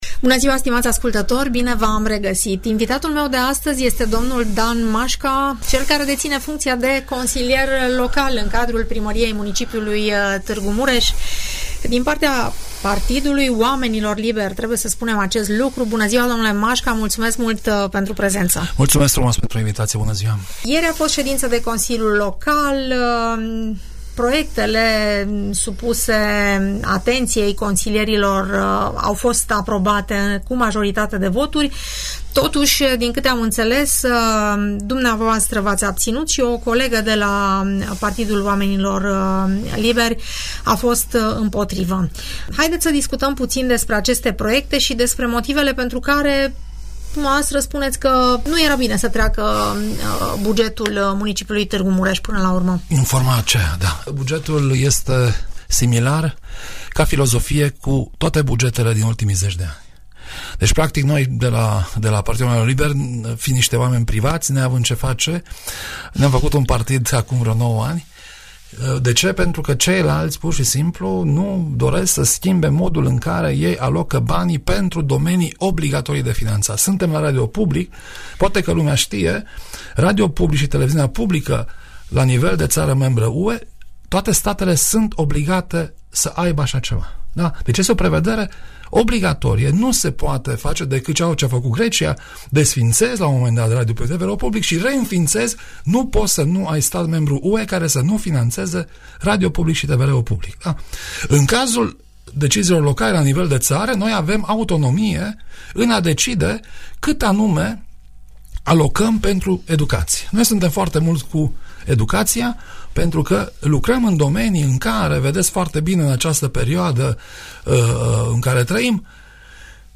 » Detalii din ședința CL a Municipiului Tg. Mureș Detalii din ședința CL a Municipiului Tg. Mureș Explicații pe marginea Hotărârilor supuse aprobării în ședința ordinară de ieri a Consiliului Local Tg. Mureș, primim de la dl Dan Mașca, reprezentant al Partidului Oamenilor Liberi în acest for.